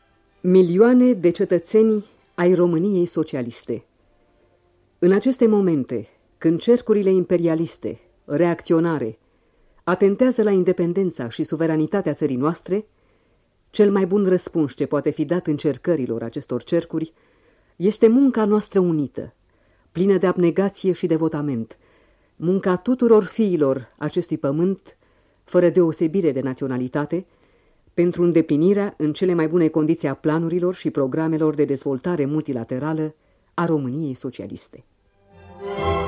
Poate doar cele 30 de secunde cu mesajul înregistrat de o voce caldă, feminină, ar putea să ne dea înțeles că protestele din ultimele zile nu au rămas fără ecou.